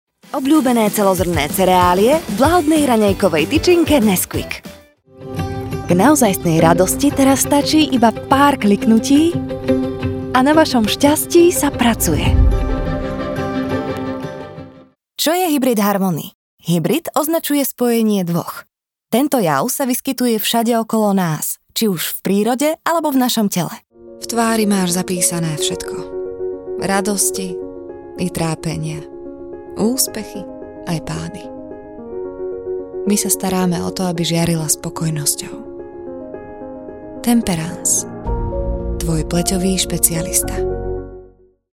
Female 20s , 30s , 40s American English (Native) Approachable , Assured , Authoritative , Bright , Bubbly , Character , Cheeky , Confident , Conversational , Cool , Corporate , Deep , Energetic , Engaging , Friendly , Funny , Gravitas , Natural , Posh , Reassuring , Sarcastic , Smooth , Soft , Upbeat , Versatile , Wacky , Warm , Witty , Young